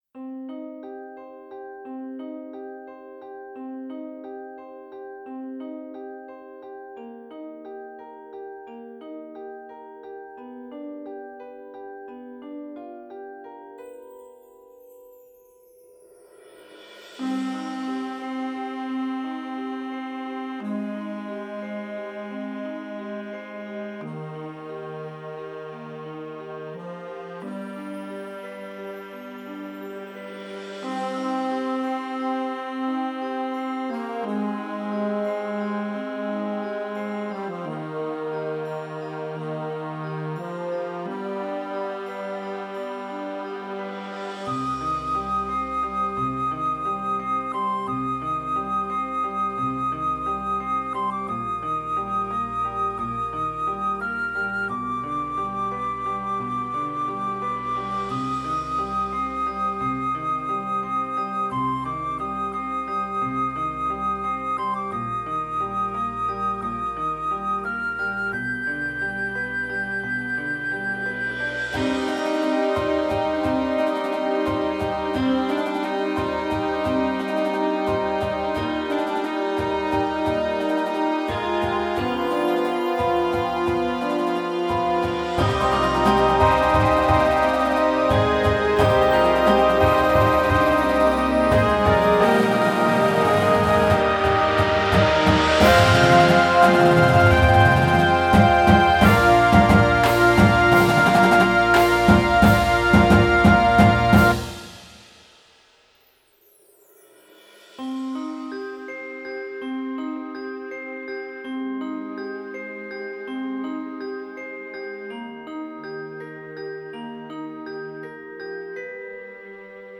Grade: Medium Instrumentation: Winds and Full Percussion